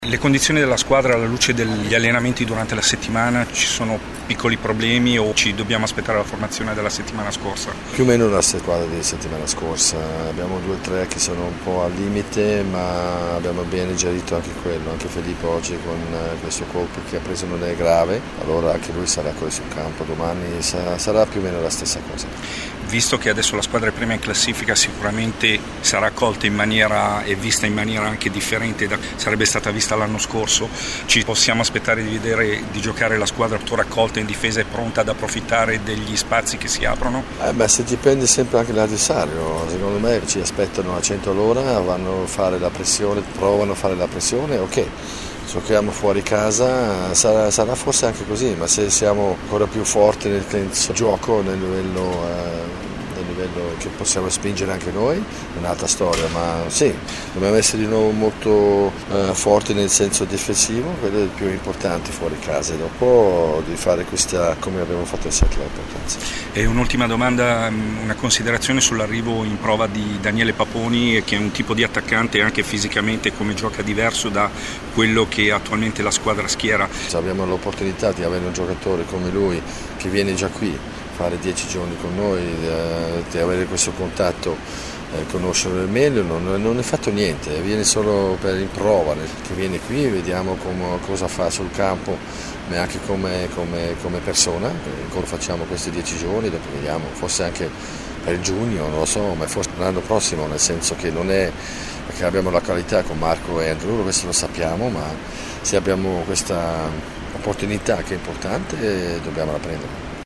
Le interviste dopo la rifinitura di questa mattina prima della partenza per il Kansas: